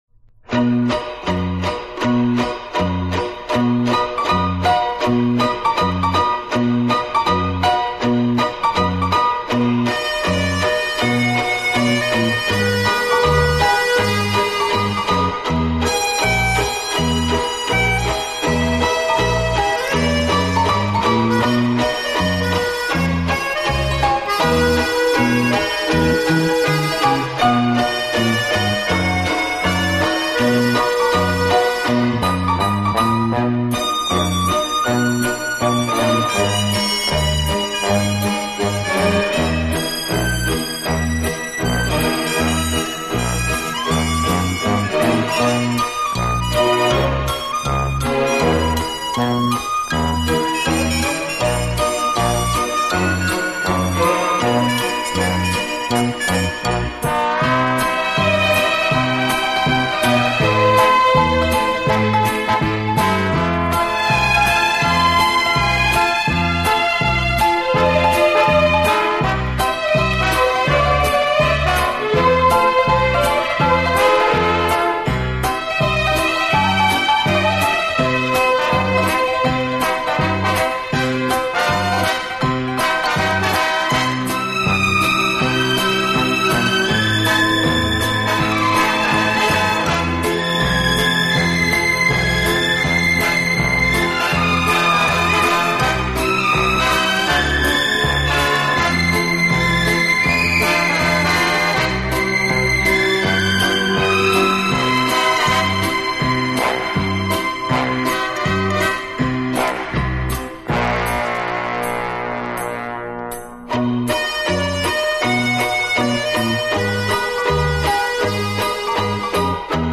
体，曲风浪漫、优雅，令人聆听時如感轻风拂面，丝丝柔情触动心扉，充分领略